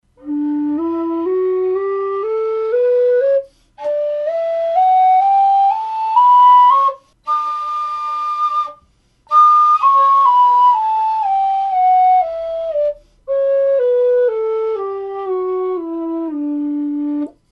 Лоу-вистл D (металл)
Лоу-вистл D (металл) Тональность: D
Модель вистла нижнего диапазона.